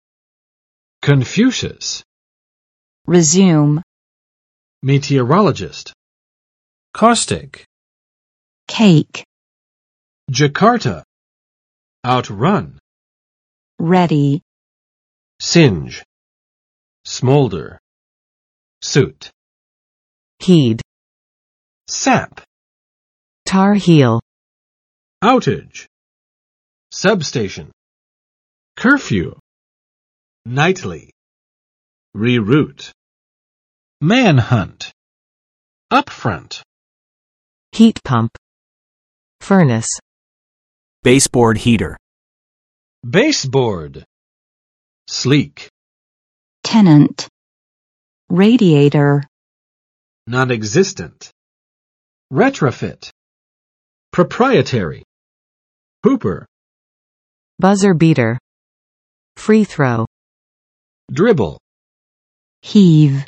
Vocabulary Test - December 6, 2022
[kənˋfjuʃəs] n. 孔子
[rɪˋzjum] v. 重新开始，继续